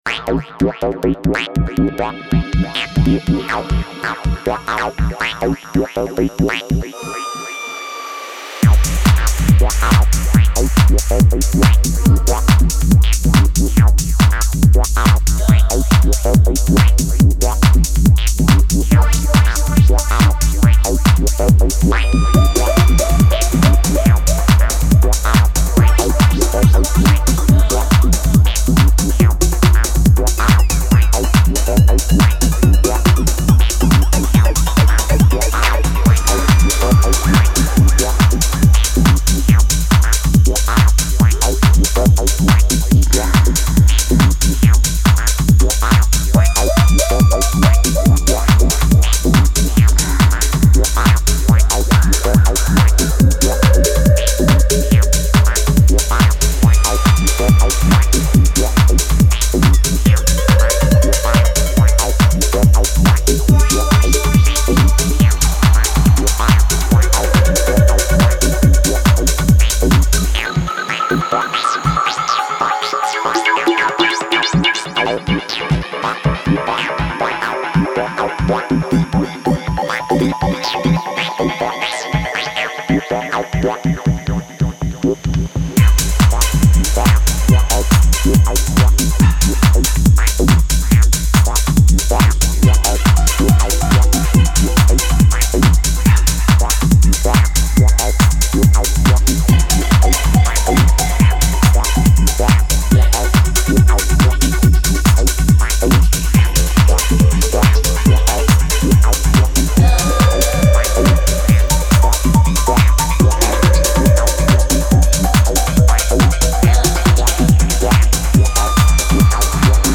seductive, trance-inducing dance music in mesmeric fashion
Locked-in, hypnotic fare with subtle gear shifts
trippy atmospherics and urgent dancefloor dynamics